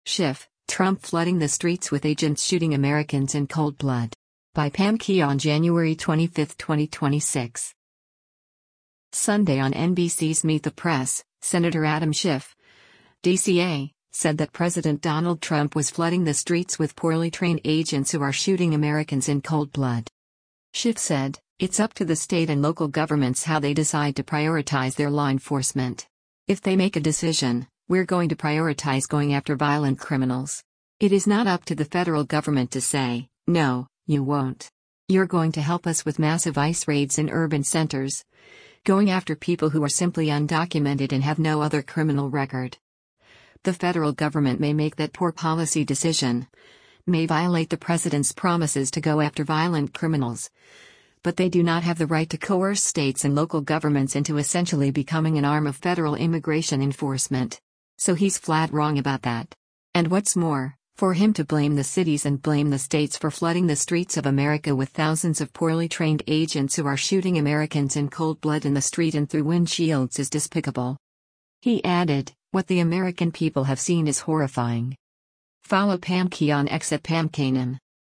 Sunday on NBC’s “Meet the Press,” Sen. Adam Schiff (D-CA) said that President Donald Trump was flooding the streets with “poorly trained agents who are shooting Americans in cold blood.”